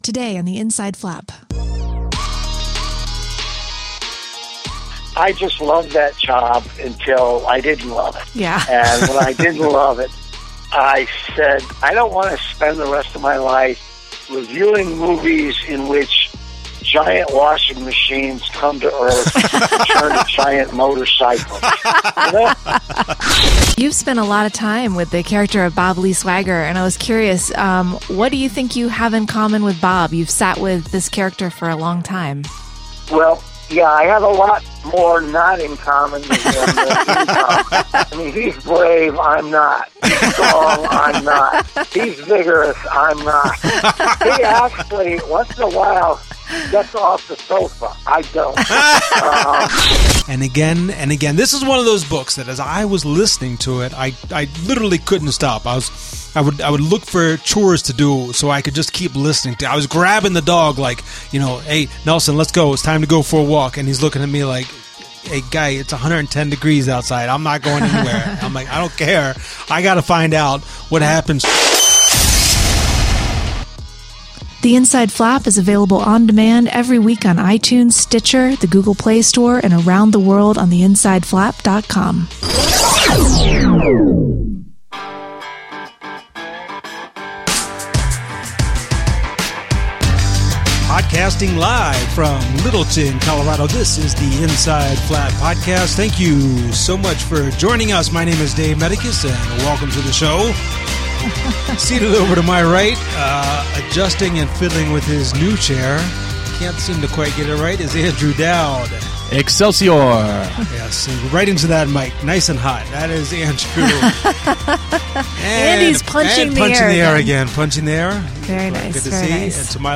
Author Stephen Hunter joins us for a chat about giving the readers what they want, the golden age of movie reviewing, and his new book Game of Snipers.